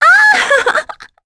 Talisha-Vox_Happy2.wav